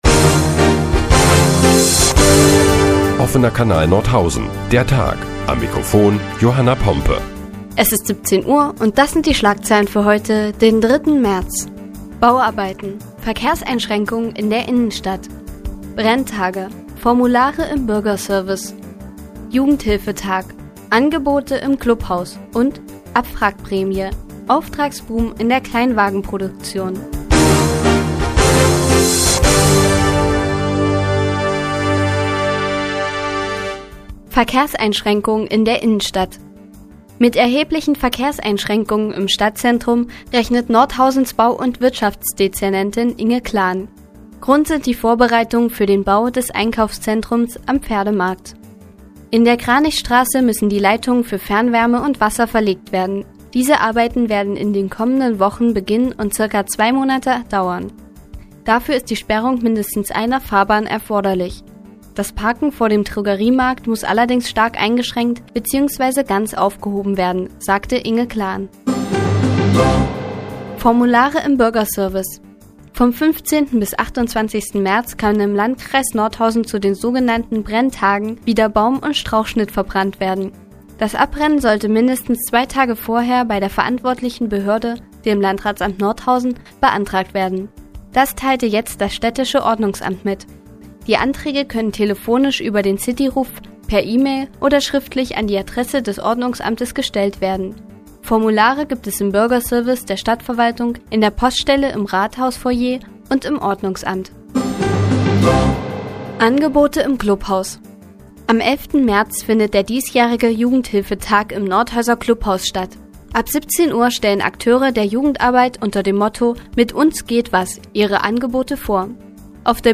Die tägliche Nachrichtensendung des OKN ist nun auch in der nnz zu hören. Heute geht es unter anderem um Verkehrseinschränkungen in der Innenstadt und die bevorstehenden Brenntage.